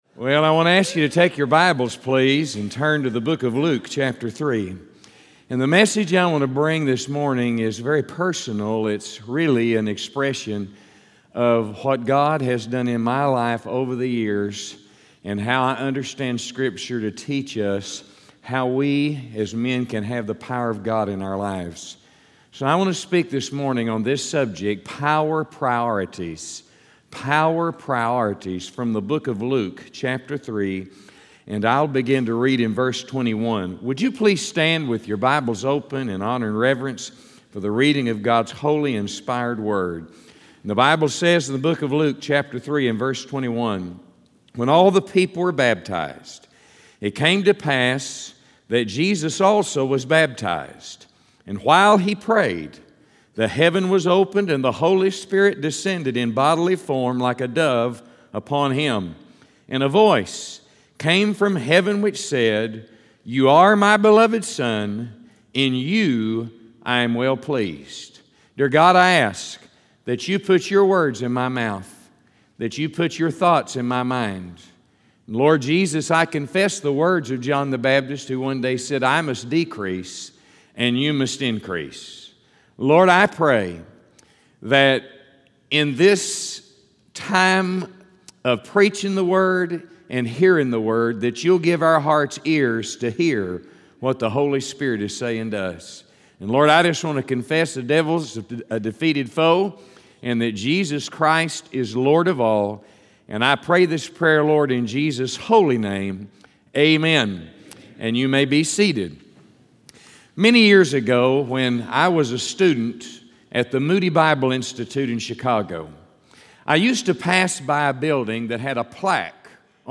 From the Real Momentum conference on Saturday, August 18, 2018